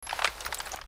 aug_draw.ogg